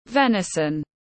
Thịt nai tiếng anh gọi là venison, phiên âm tiếng anh đọc là /ˈvɛnzn/
Venison /ˈvɛnzn/